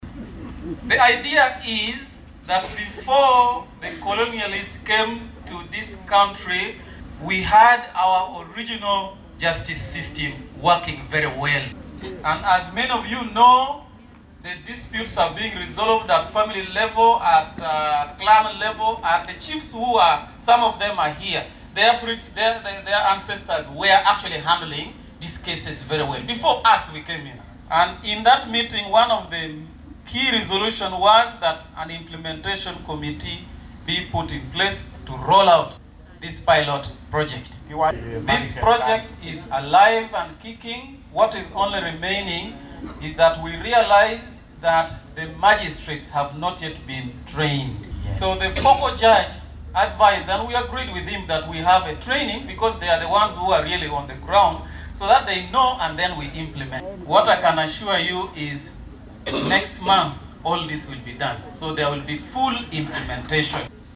Voice-2: Justice Odoki says ADR is God-sent.